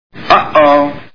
Rain Man Movie Sound Bites
uh_oh.wav